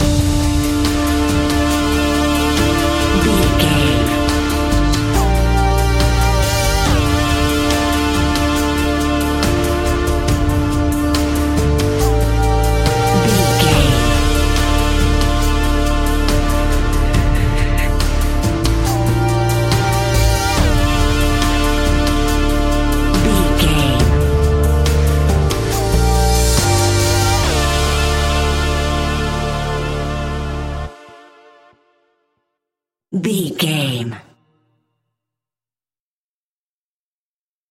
Aeolian/Minor
DOES THIS CLIP CONTAINS LYRICS OR HUMAN VOICE?
WHAT’S THE TEMPO OF THE CLIP?
ominous
suspense
eerie
synthesiser
percussion
drums
electronic music